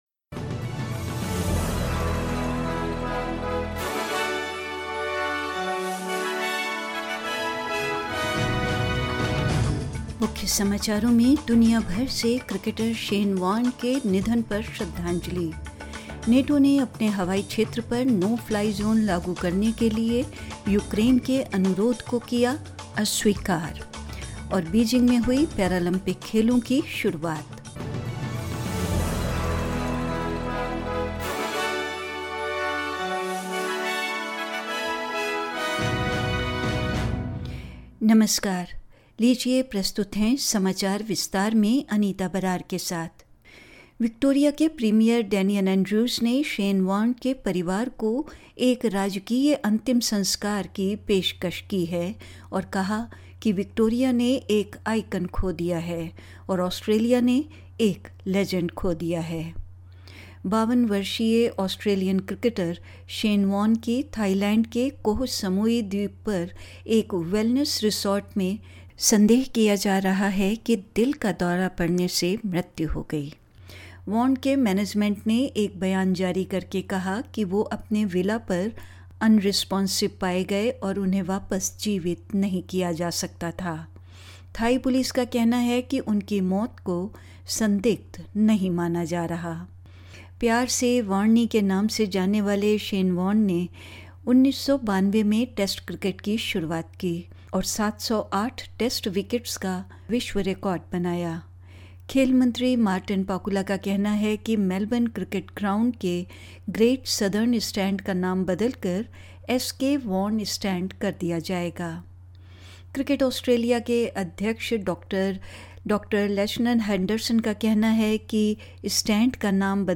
SBS Hindi News 05 March 2022: Tributes are pouring in following Cricketer Shane Warne's death